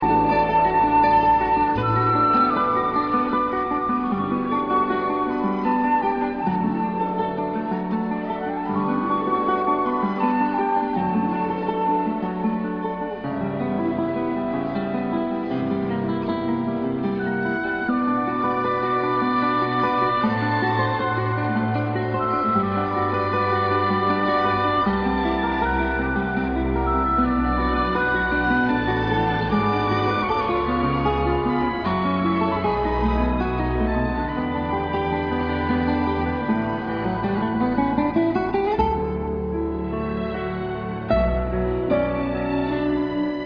the absolutely beautiful theme performed on guitar